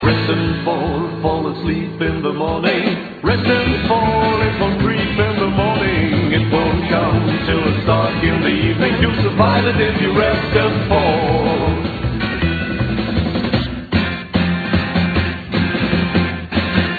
Flotter Neo-Rockabilly aus Berlin auf einem Schweizer Label.